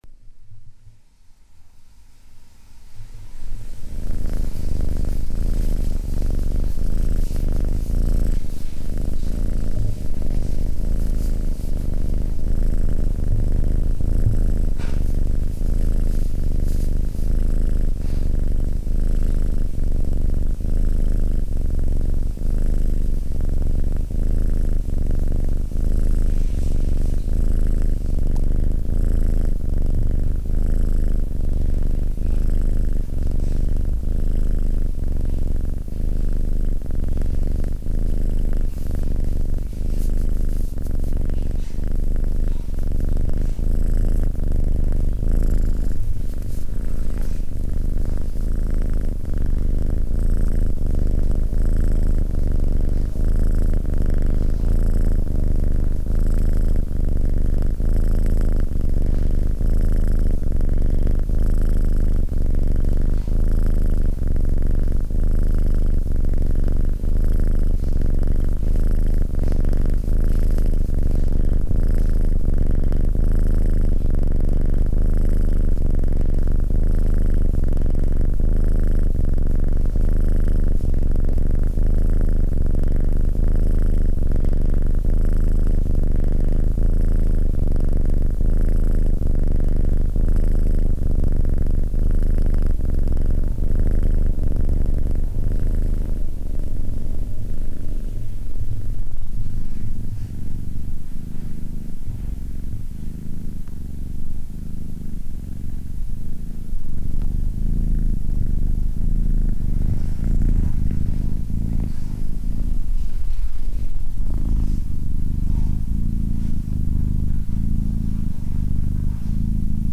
Мурчание_Кошки_-_Мурчание_Кошки
Murchanie_Koshki___Murchanie_Koshki.mp3